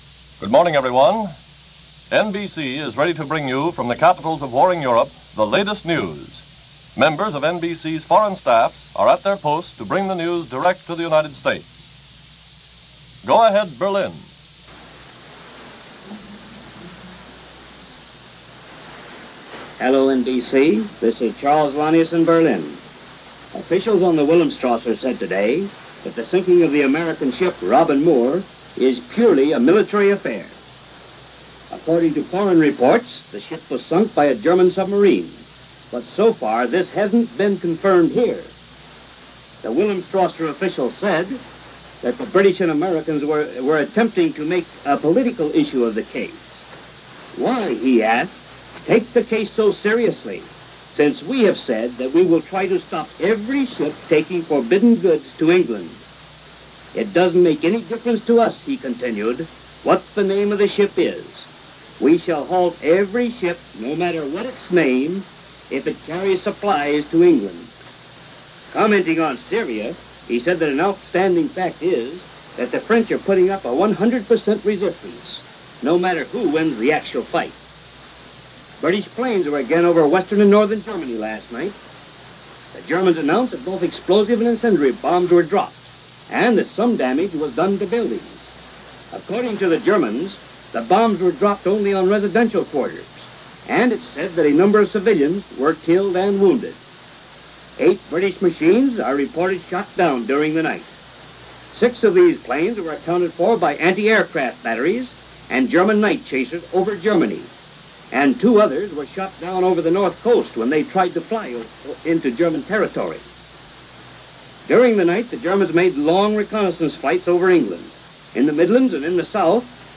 Dogfights Over Malta -Sinking U.S. Cargo Ships - Raids Over Germany - June 13, 1941 - Reports from NBC's News Of The World.